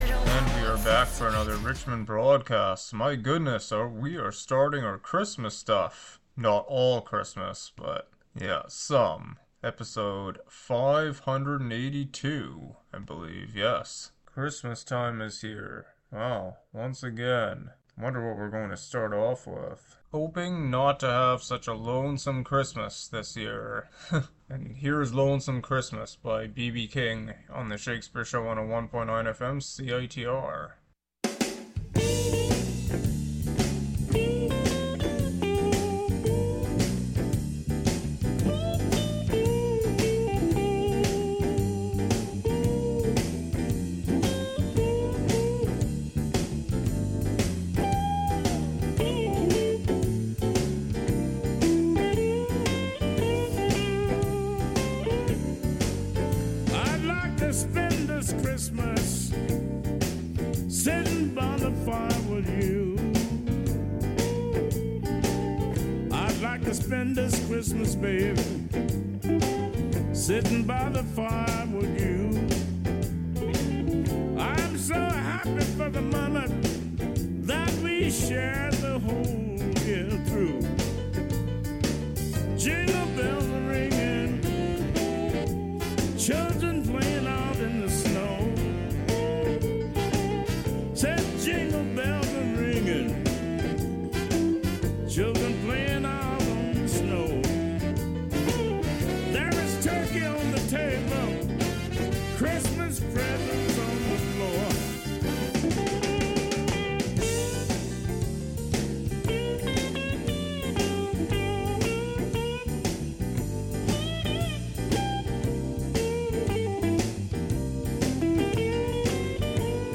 an eclectic mix of music, some Christmas